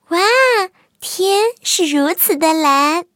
野牛II司令部语音2.OGG